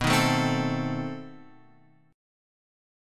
B 7th Flat 9th